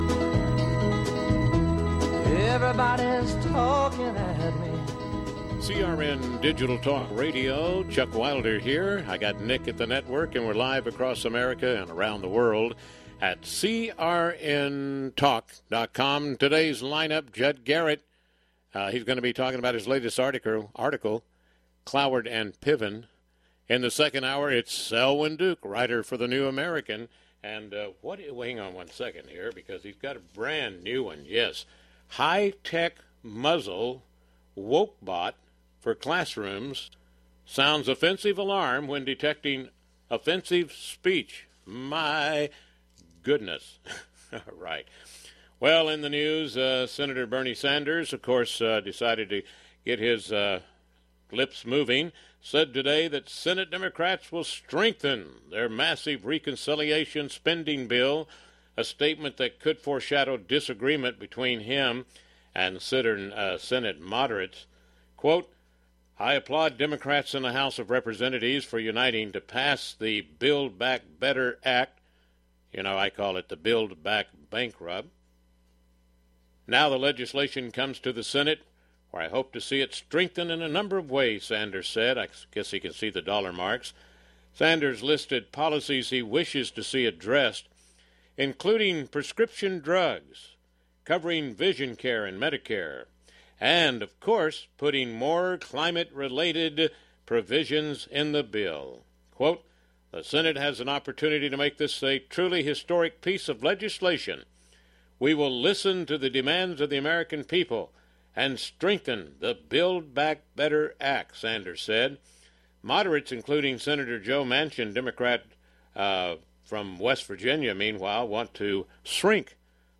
I was on the second hour of the show.